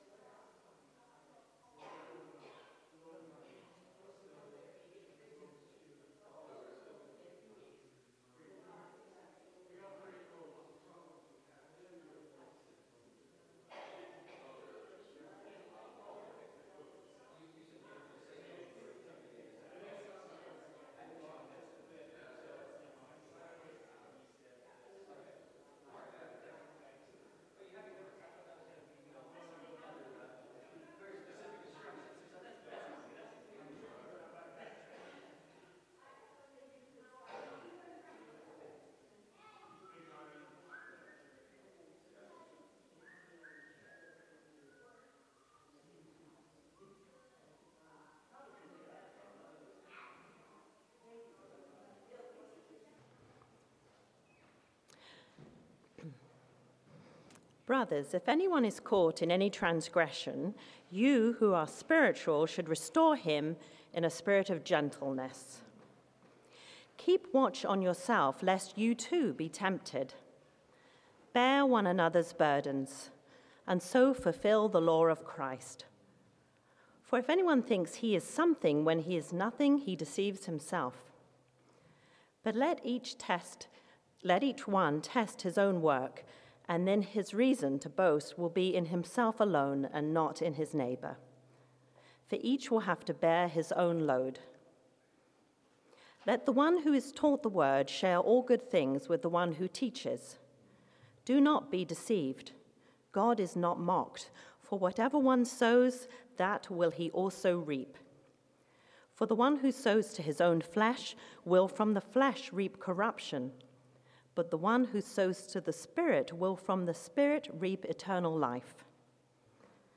Passage: Galatians 6:7-10 Sermon